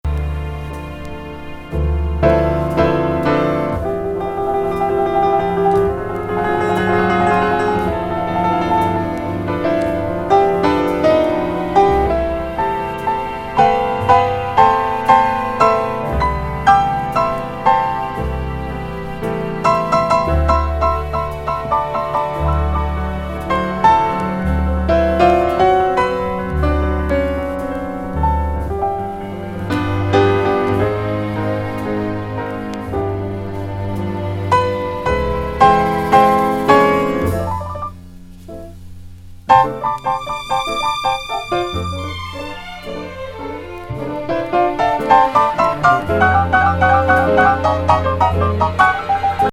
ピアノ・トリオ編成に加え、ストリングス・セクションも参加。
幻想的で儚いムードを醸す